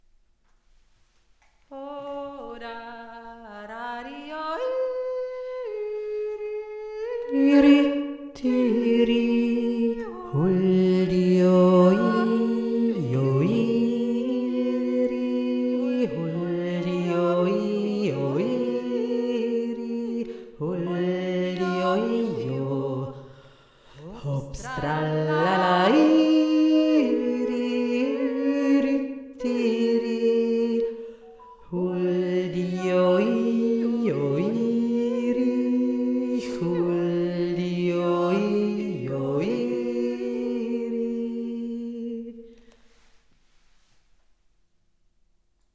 die Tiefe